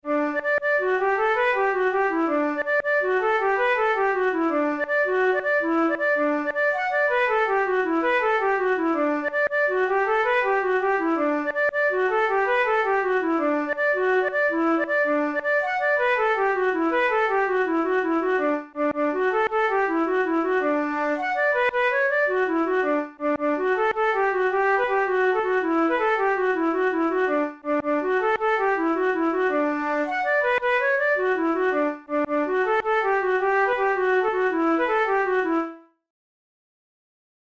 InstrumentationFlute solo
KeyD major
RangeD4–F#5
Time signature6/8
Tempo108 BPM
Jigs, Traditional/Folk
Traditional Irish jig